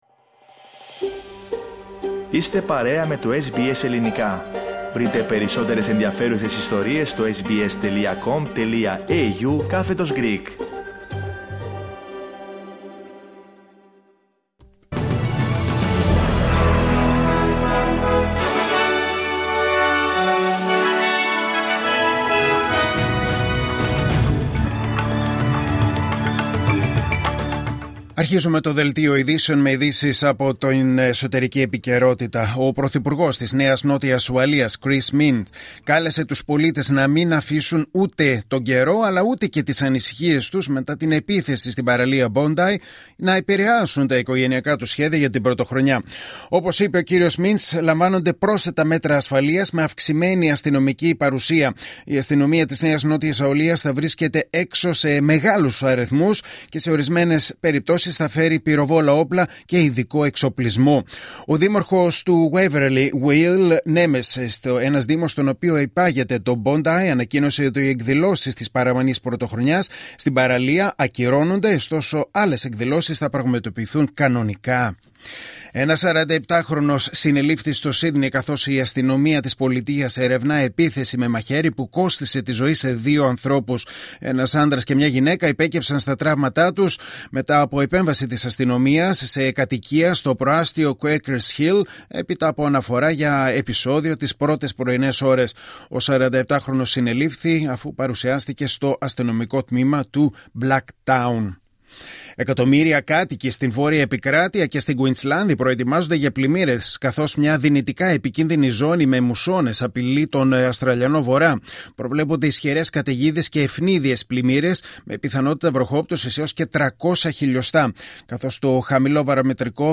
Δελτίο Ειδήσεων Κυριακή 28 Δεκεμβρίου 2025